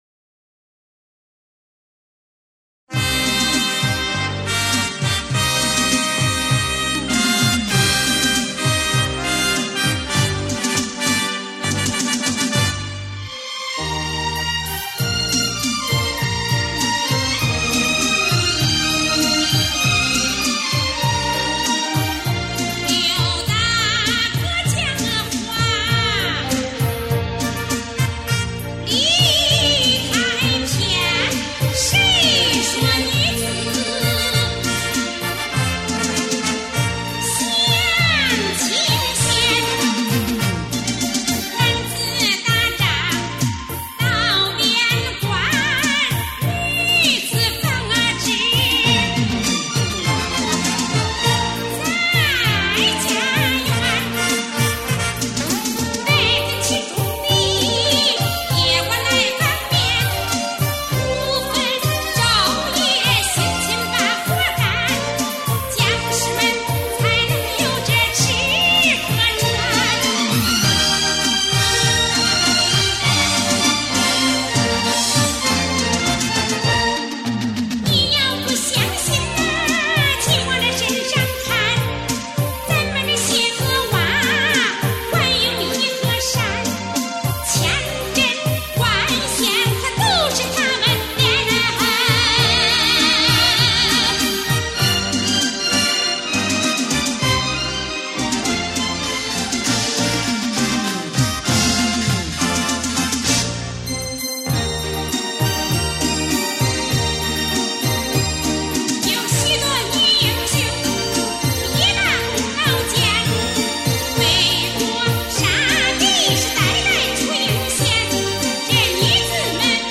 [14/8/2011]史无前例的电声伴奏【谁说女子不如男】